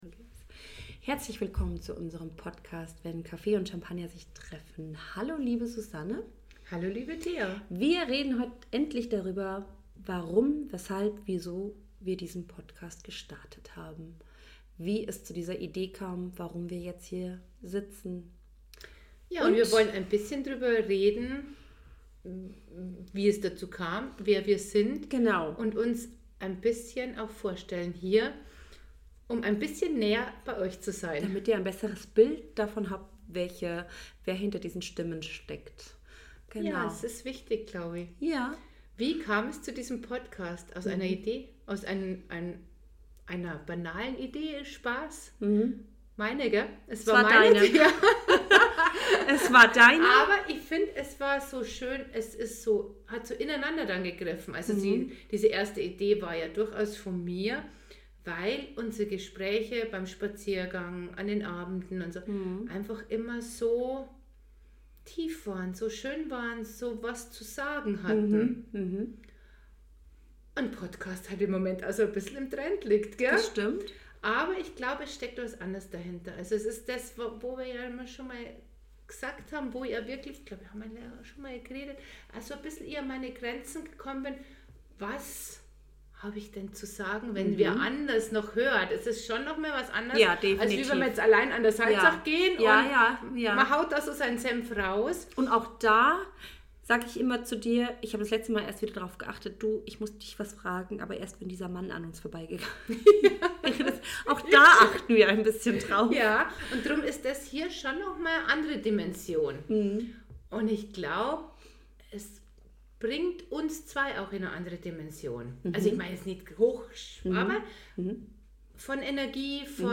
Zwei Frauen die dich in ihre Gedanken mitnehmen.